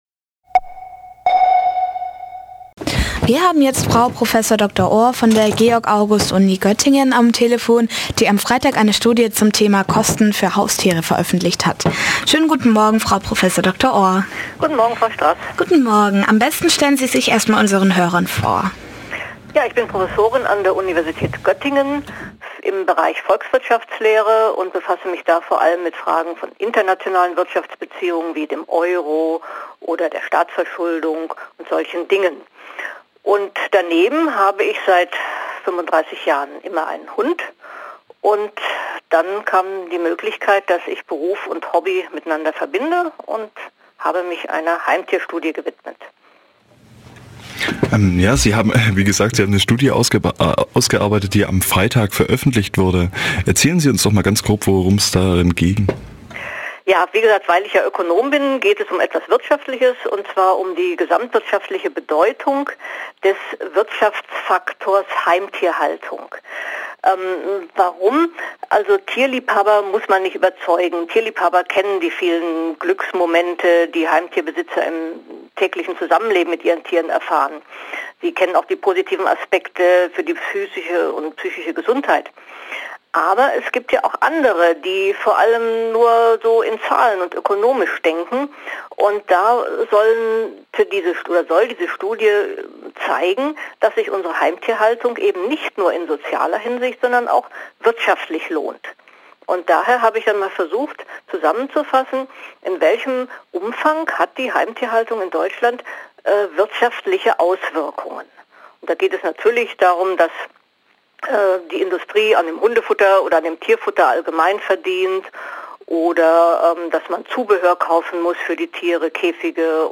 Genre Radio
interview_heimtierstudie.mp3